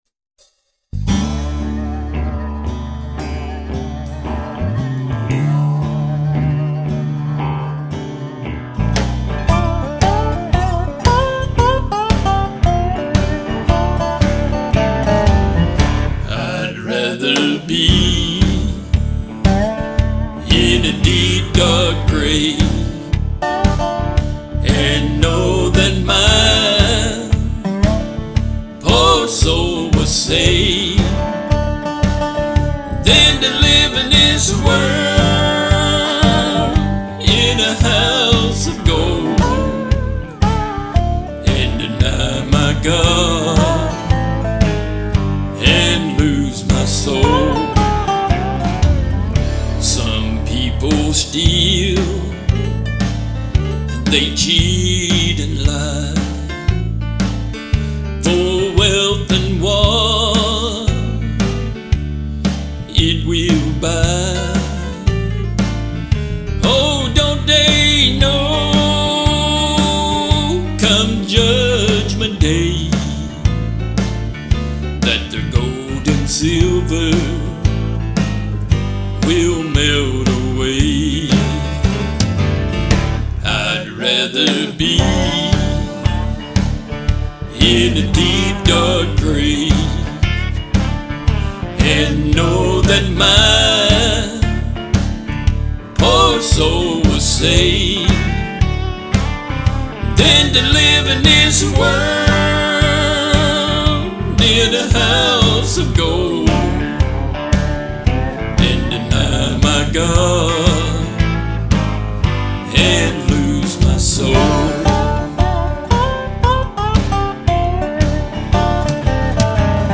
House Of Gold (Up-Tempo)